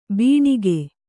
♪ bīṇiga